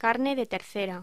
Locución: Carne de tercera
voz